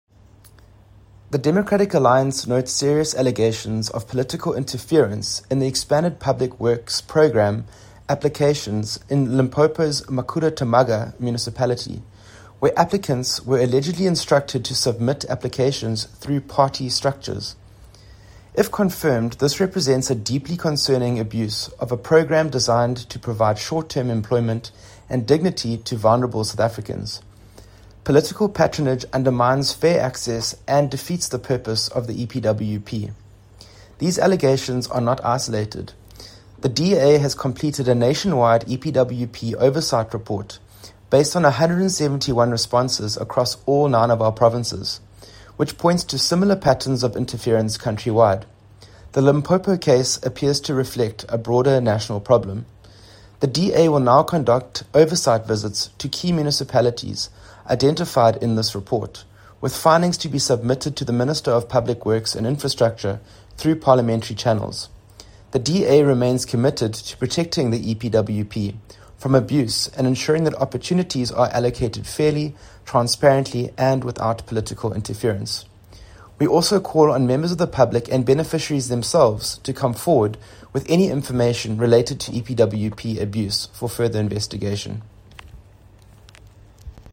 English by Edwin Bath MP.
Edwin-Bath-MP.mp3